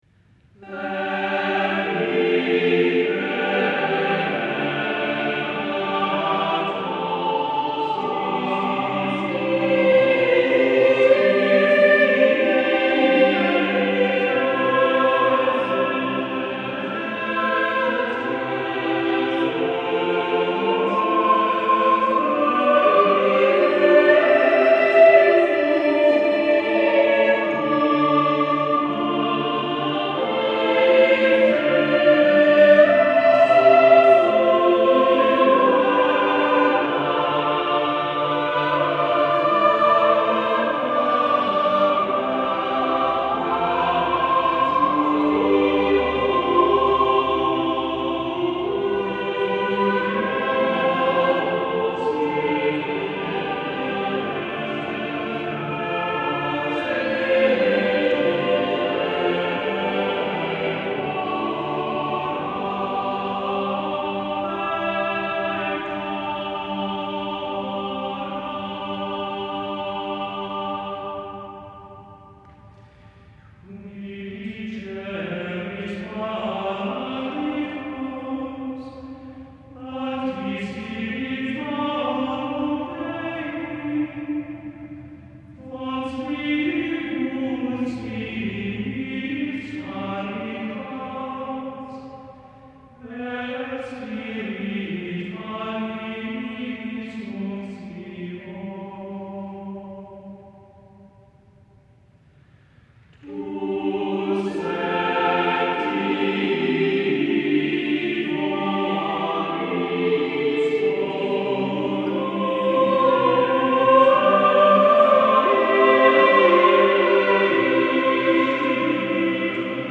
A further polyphonic setting of a Pentecost hymn by de Alva is on the well known chant “Veni creator”, sounding again in the soprano part.
Four of seven stanzas are sung, alternating plainchant and polyphony.